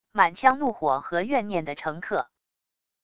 满腔怒火和怨念的乘客wav音频